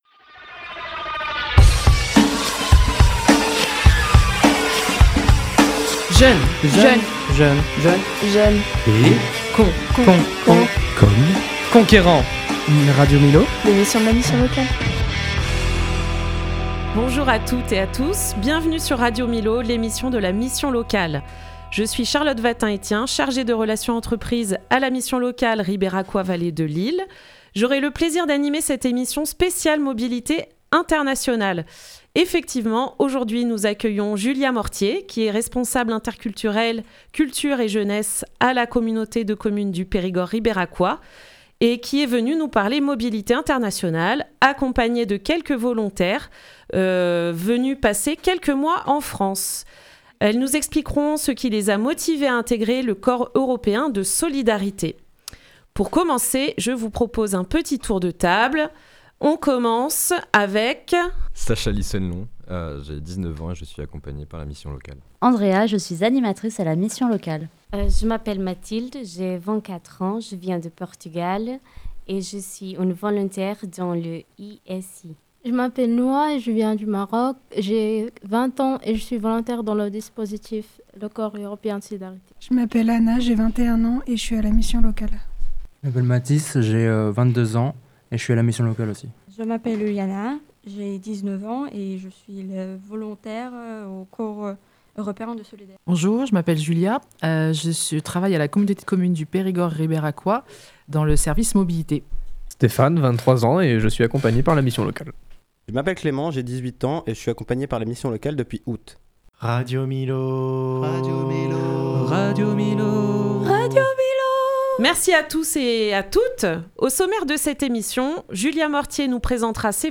La mission locale et des jeunes prennent le micro.